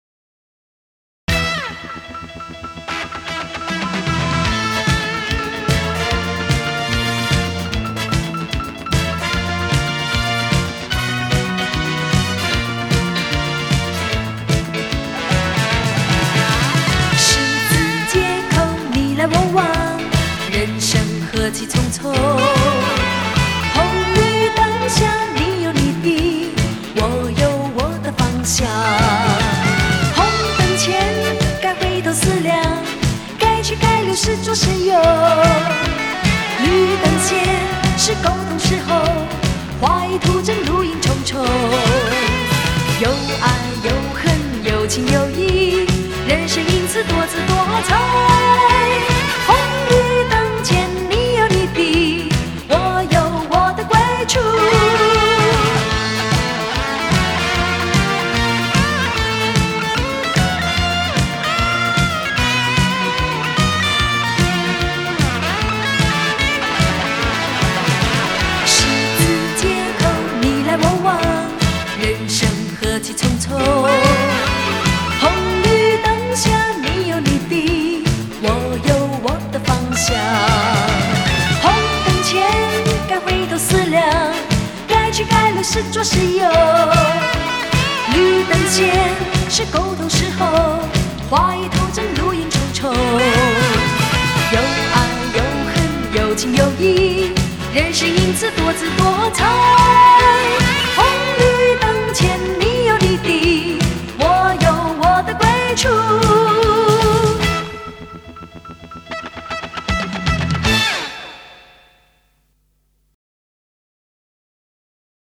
她的容貌不属于那种妩媚甜妹，她的音质也不清亮或高亢，但是她朴实的相貌让我觉得亲切，她唱歌的投入以及对歌曲情境的把握到位。